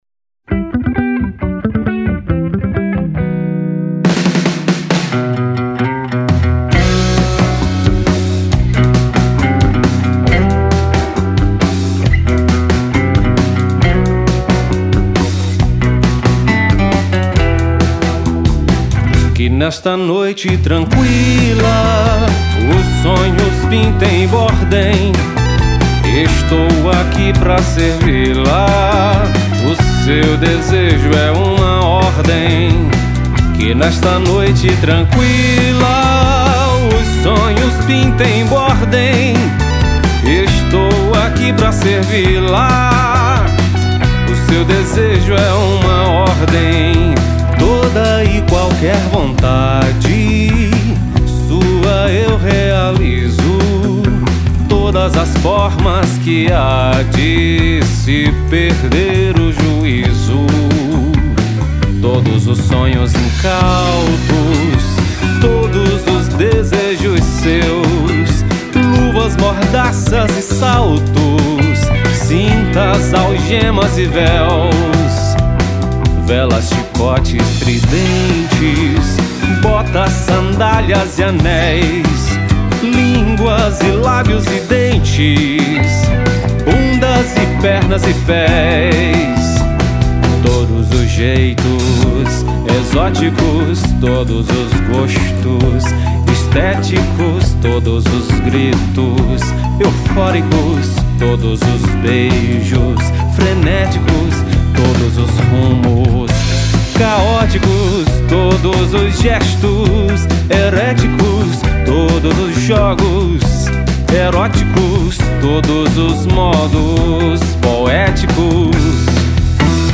1275   03:28:00   Faixa:     Rock Nacional